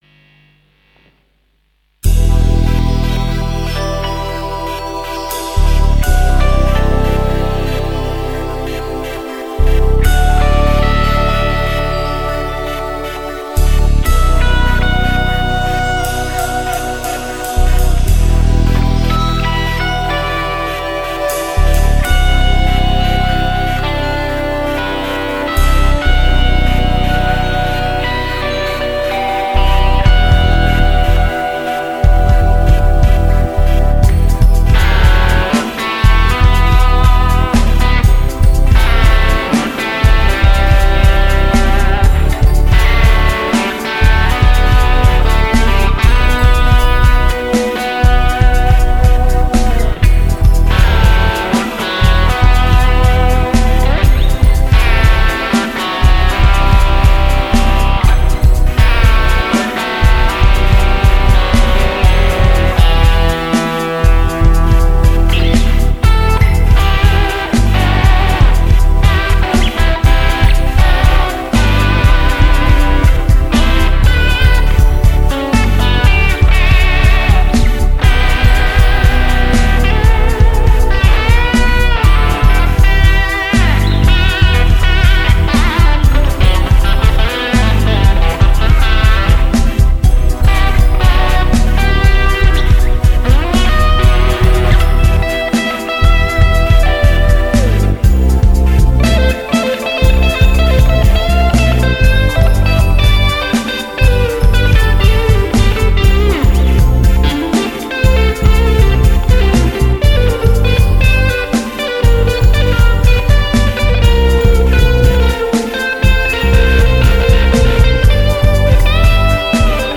Wie (fast) immer in einem Rutsch durchgespielt, Schrägheiten sind manchmal unbeabsichtigt, manchmal aber gewollt :-)